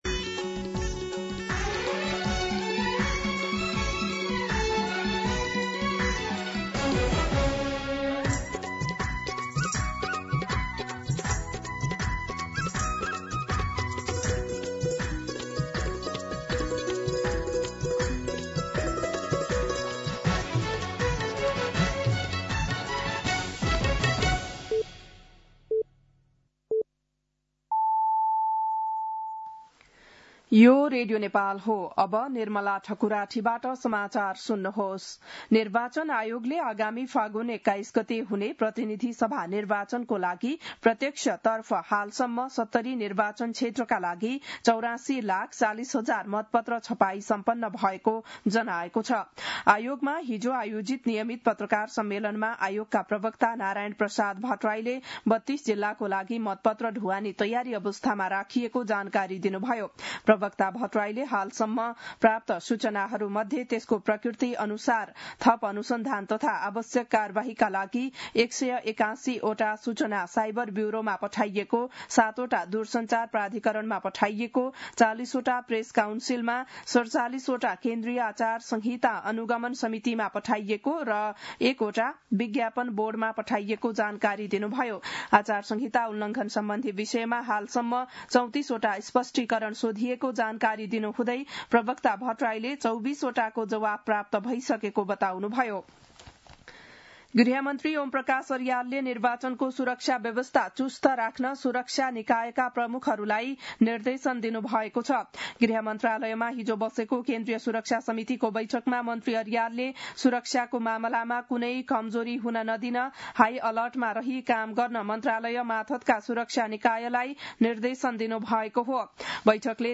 बिहान ११ बजेको नेपाली समाचार : २४ माघ , २०८२
11-am-Nepali-News-1.mp3